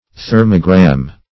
Thermogram \Ther"mo*gram\, n.